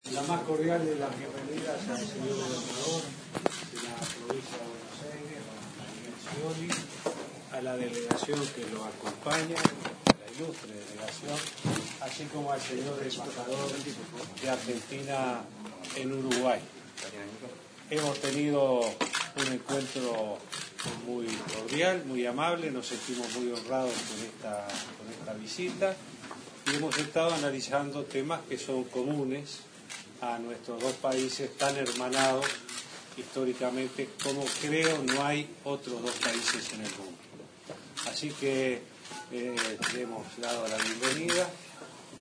Habla Vázquez